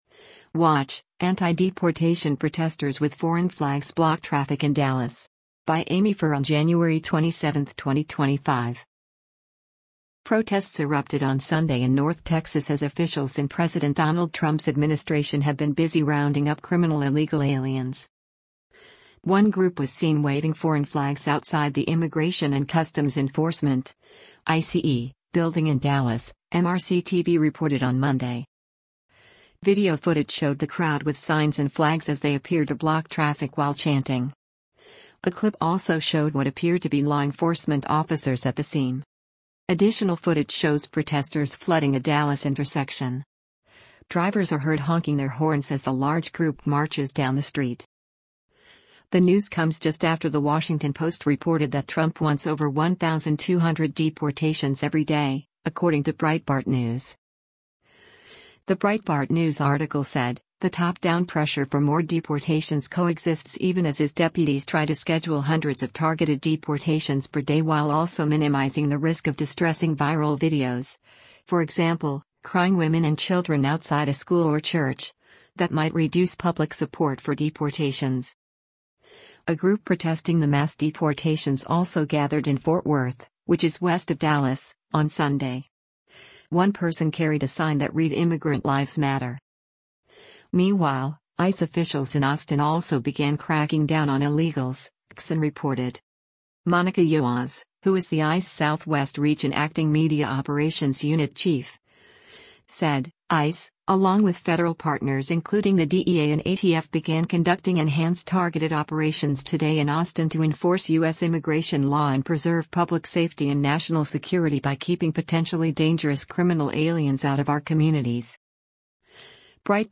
Video footage showed the crowd with signs and flags as they appeared to block traffic while chanting.
Drivers are heard honking their horns as the large group marches down the street: